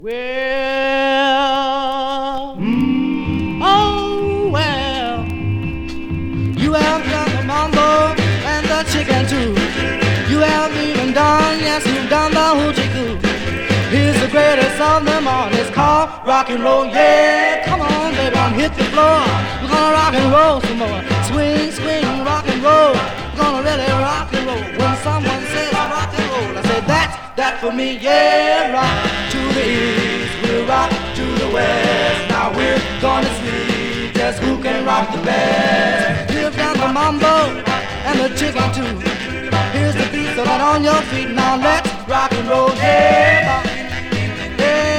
勢いを感じるボーカルワークと。
Doo Wop, Rhythm & Blues　UK　12inchレコード　33rpm　Mono